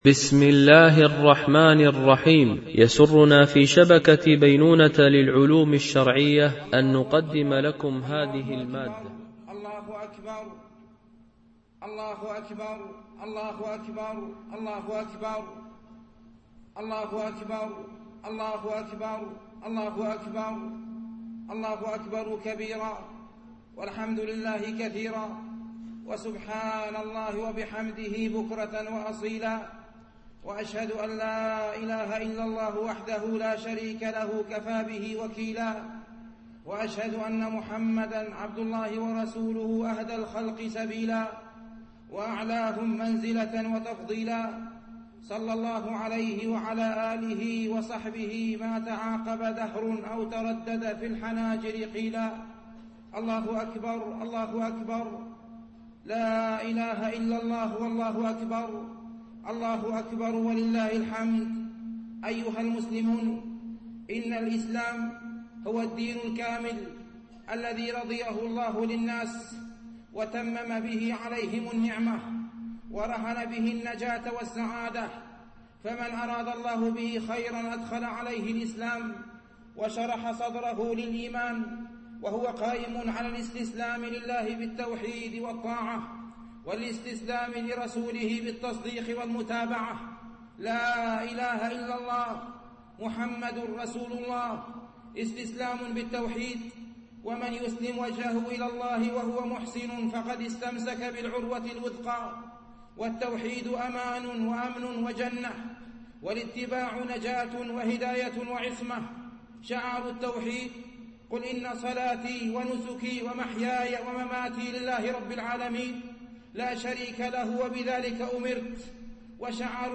خطبة عيد الفطر (1438 هـ)